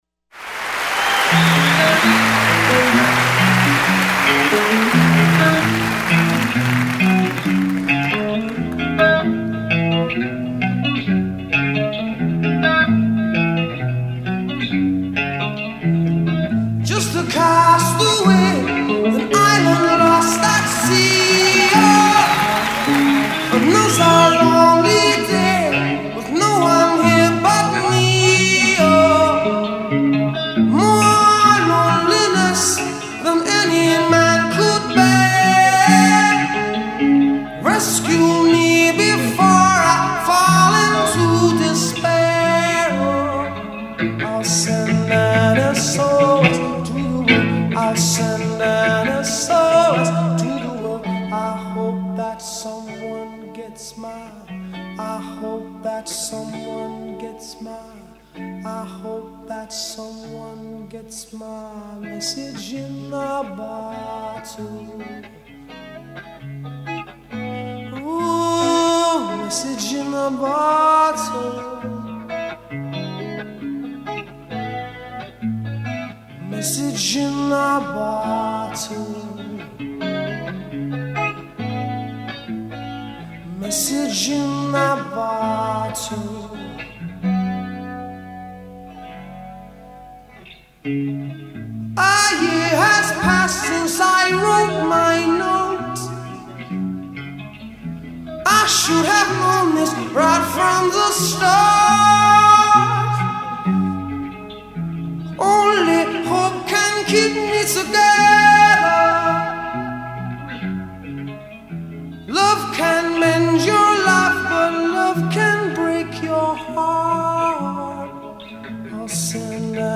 solo performance